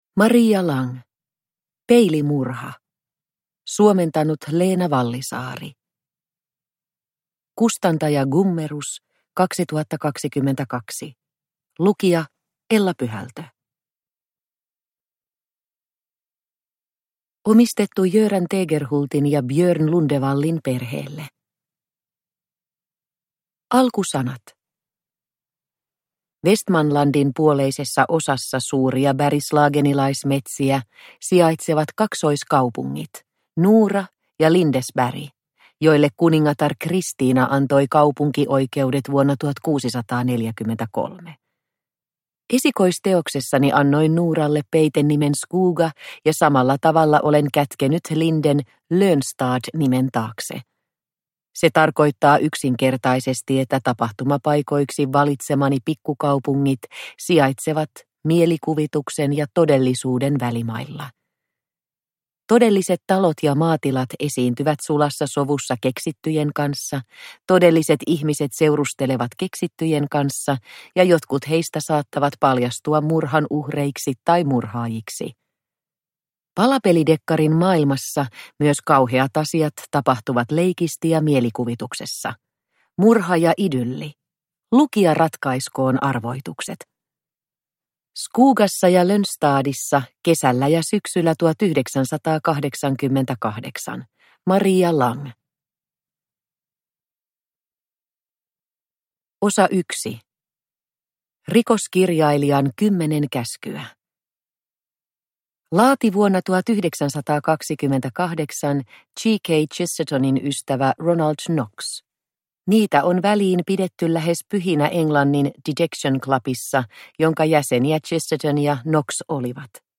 Peilimurha – Ljudbok – Laddas ner